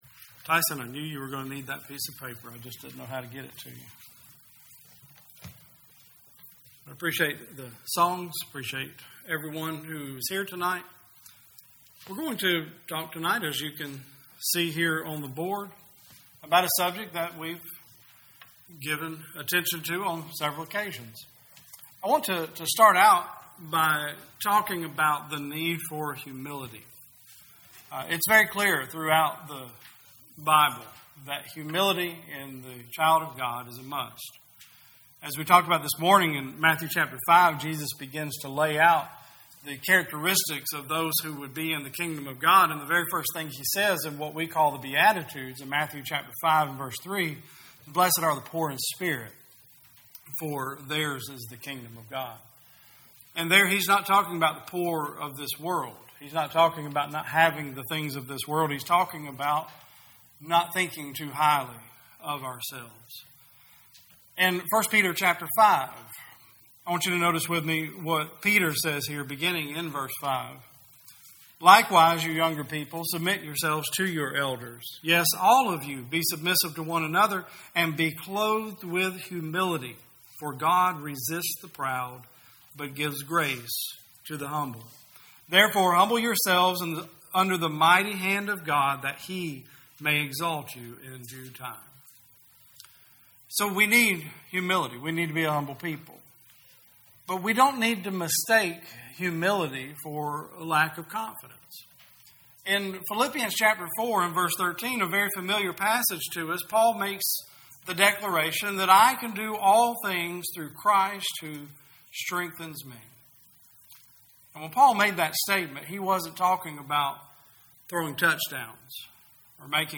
2019 Service Type: Sunday Service Preacher